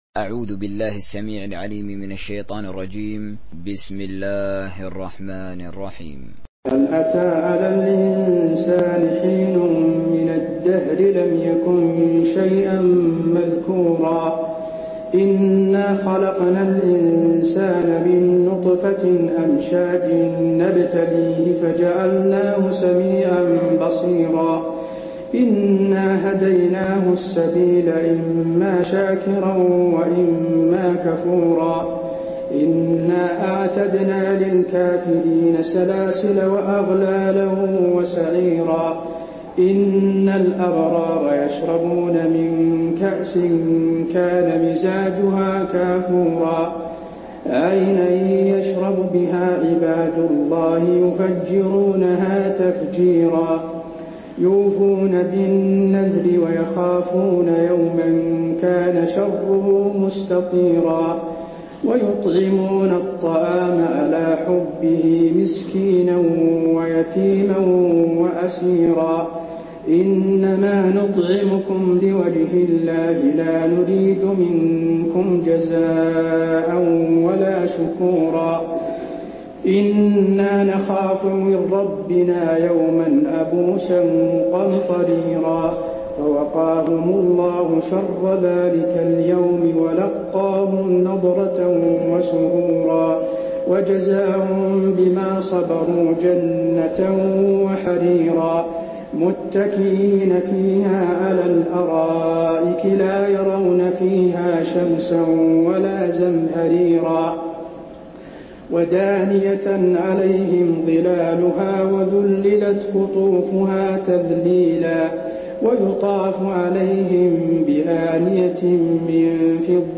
تحميل سورة الإنسان حسين آل الشيخ تراويح